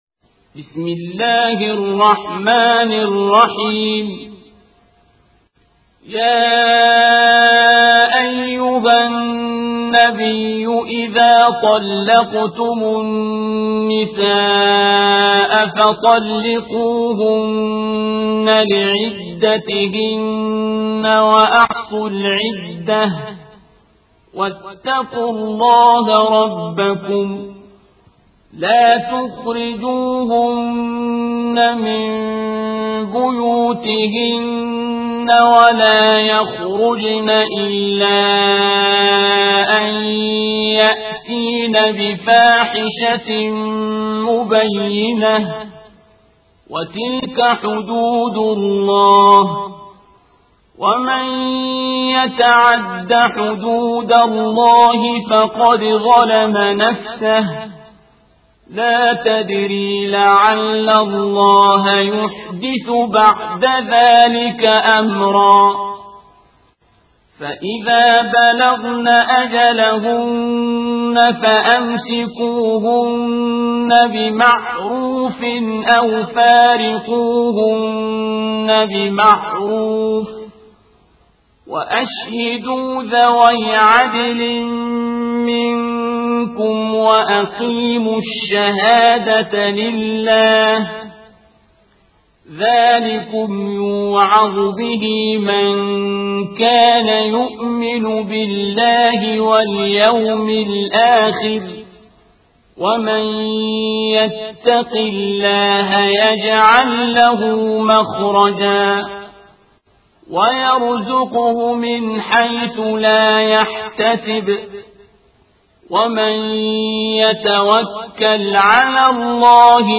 القارئ: الشيخ عبدالباسط عبدالصمد